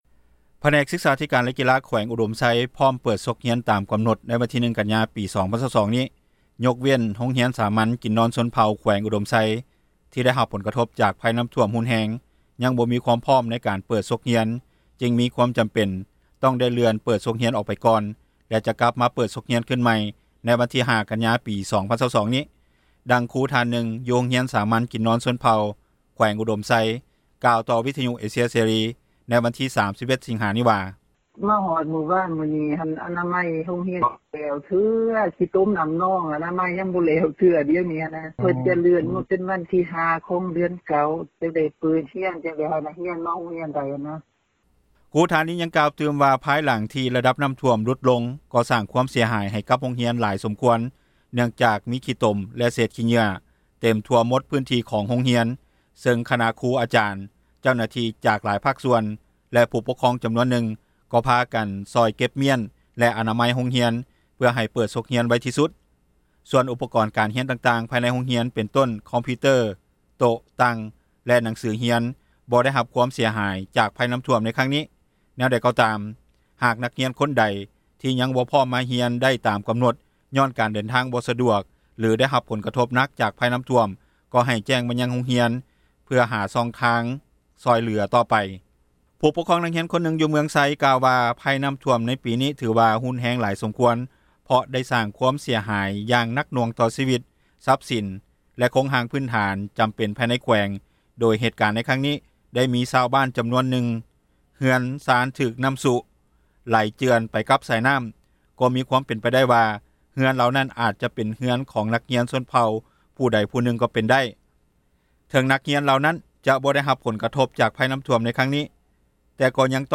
ດັ່ງຜູ້ປົກຄອງທ່ານນຶ່ງ ຢູ່ເມືອງໄຊ ແຂວງອຸດົມໄຊ ກ່າວຕໍ່ວິທຍຸເອເຊັຽເສຣີ ໃນມື້ດຽວກັນນີ້ວ່າ:
ດັ່ງເຈົ້າໜ້າທີ່ ຜແນກສຶກສາທິການ ແລະກິລາ ແຂວງອຸດົມໄຊ ກ່າວຕໍ່ວິທຍຸເອເຊັຽເສຣີ ໃນມື້ດຽວກັນນີ້ວ່າ: